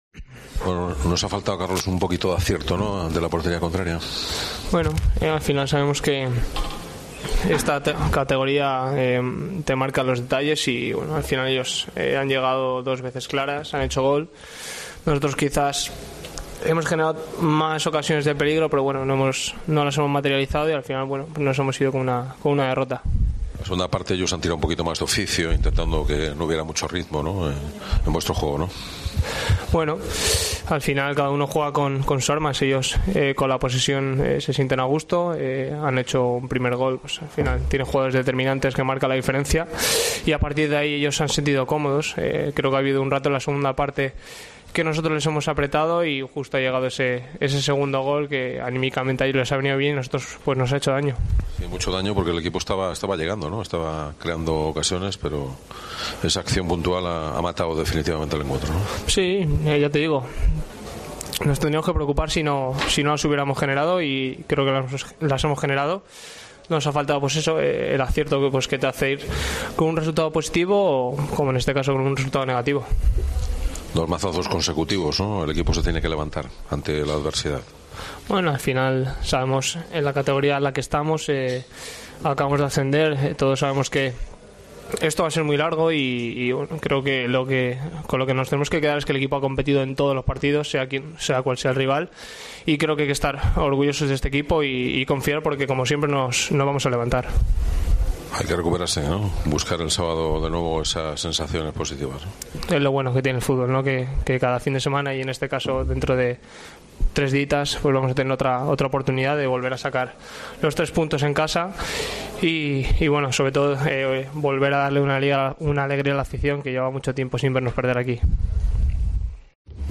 Escucha aquí las palabras de los dos jugadores de la Deportiva Ponferradina